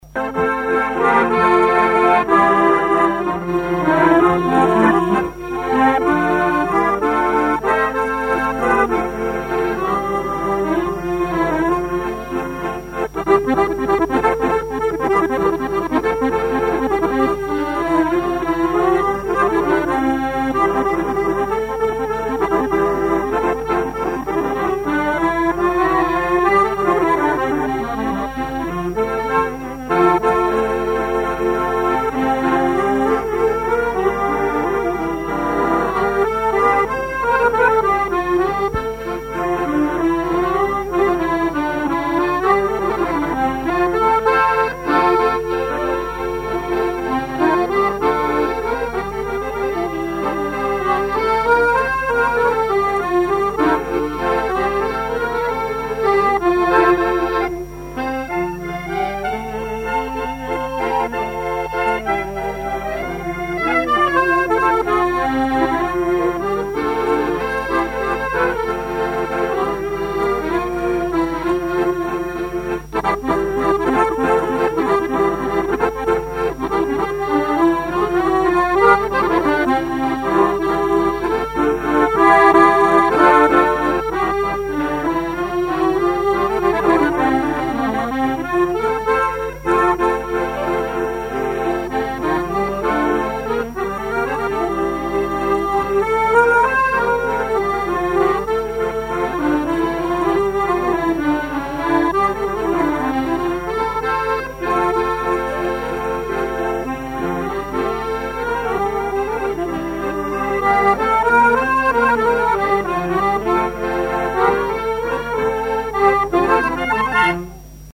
danse : valse
collectif de musiciens pour une animation à Sigournais
Pièce musicale inédite